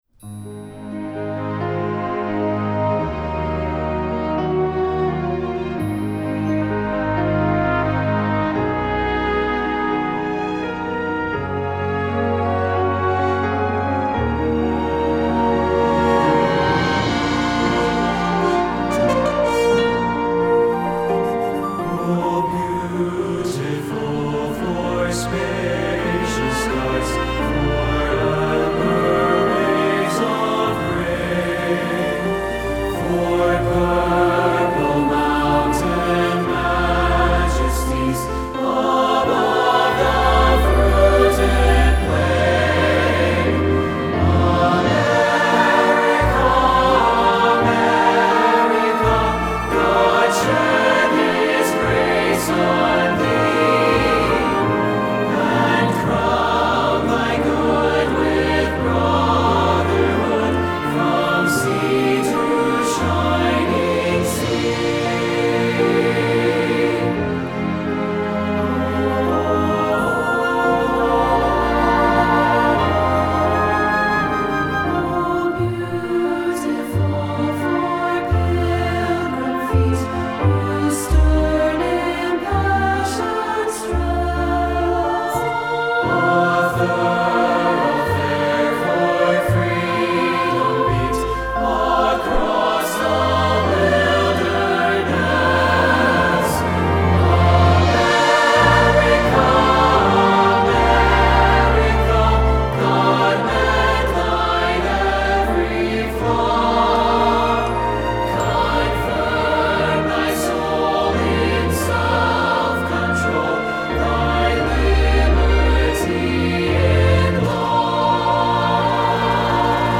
Choral
Choral Sheet Music